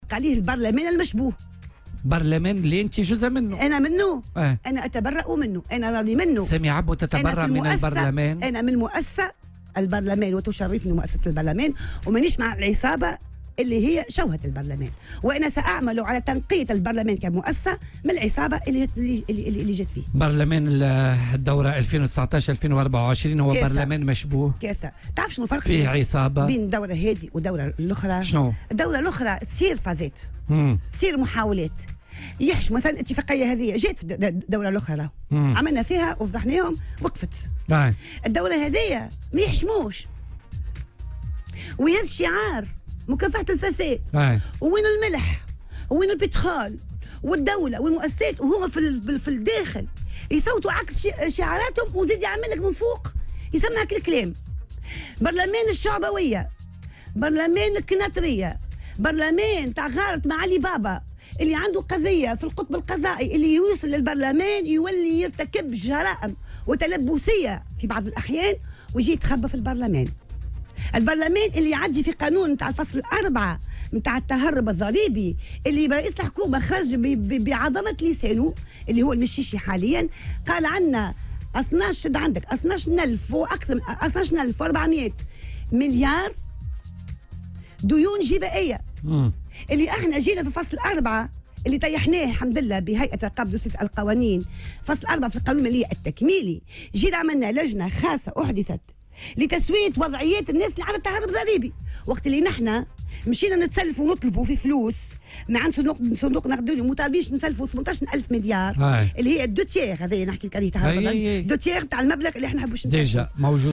وأضافت في مداخلة لها اليوم في برنامج "بوليتيكا" على "الجوهرة أف أم" أنه برلمان "الشعبوية" و"الكناترية" و"مغارة علي بابا"، وأن كل من لديهم قضايا في القطب القضائي يتخفّون فيه.